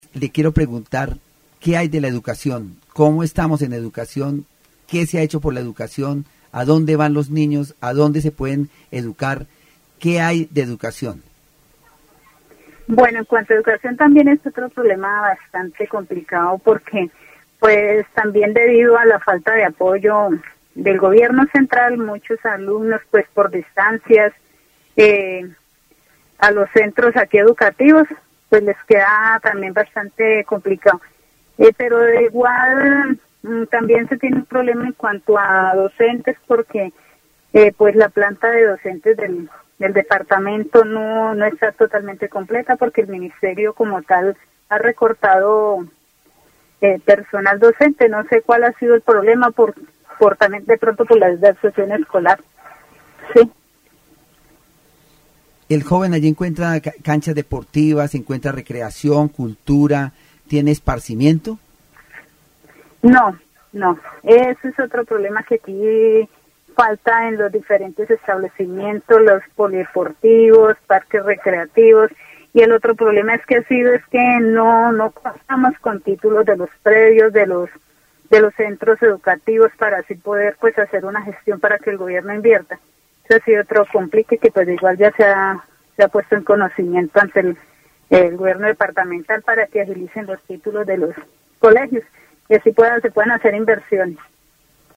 Universidad Distrital Francisco José de Caldas. Emisora LAUD 90.4 FM
Conversación sobre la educación en Vichada, abordando problemas como la falta de apoyo gubernamental, la escasez de docentes y la necesidad de mejores instalaciones recreativas y educativas.